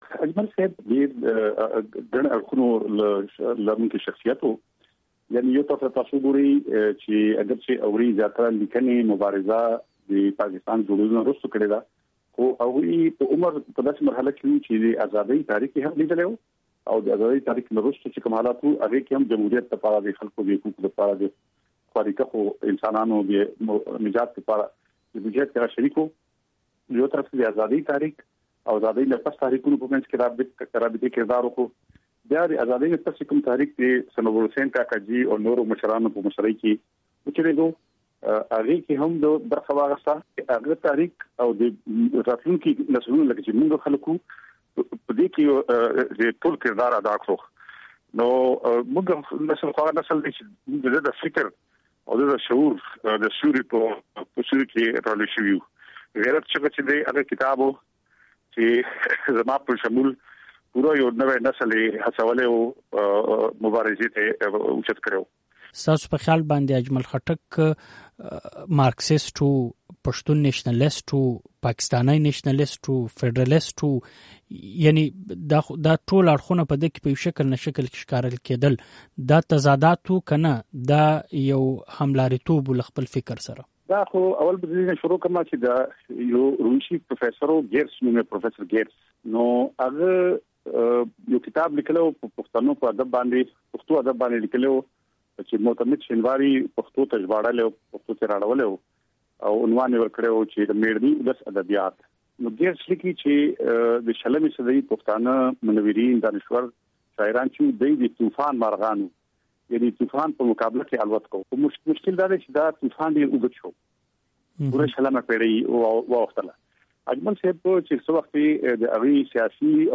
ځانګړی راپور